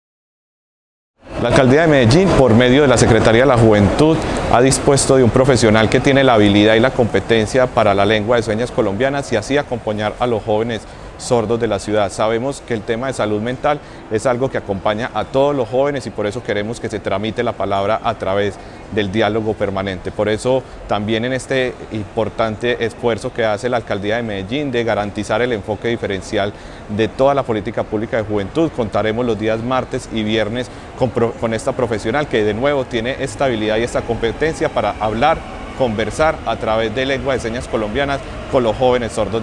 || Audio || Palabras de Alejandro Matta Herrera, secretario de la Juventud.